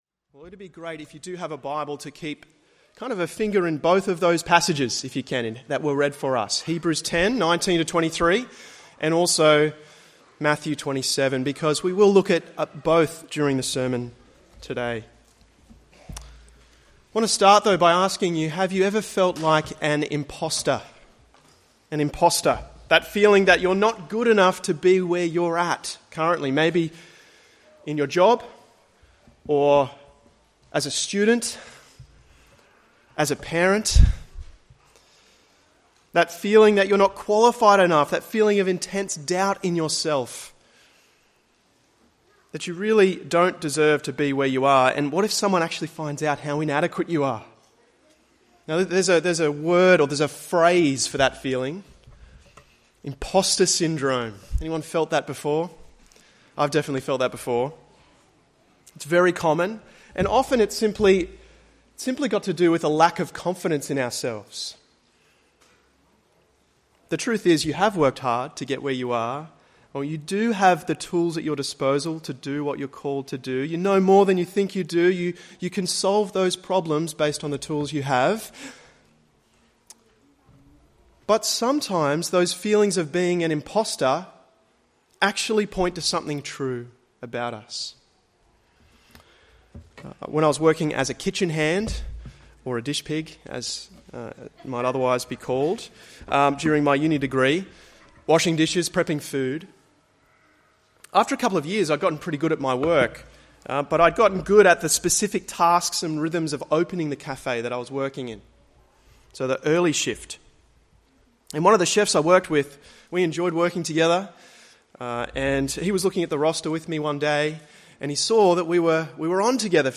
Good Friday 18 April 2025
Sermon